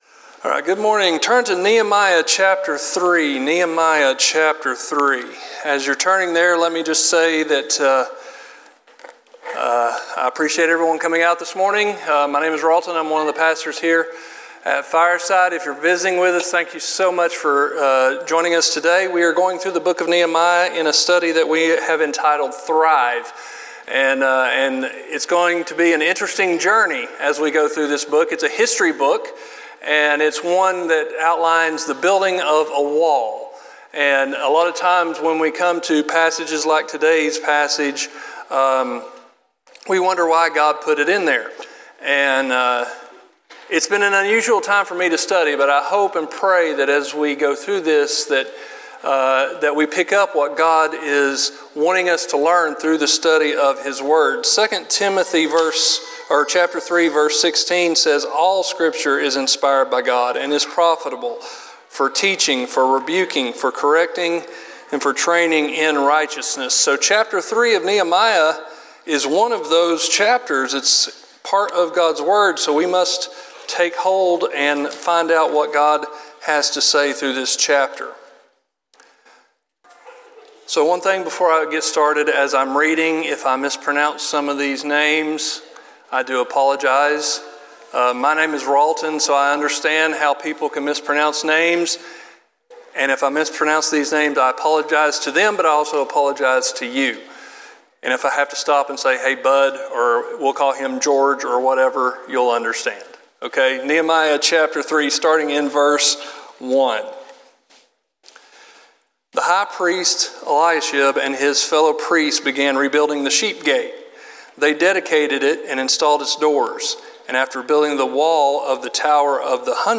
sermon series